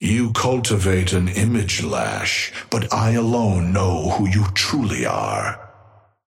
Amber Hand voice line
Patron_male_ally_lash_start_01.mp3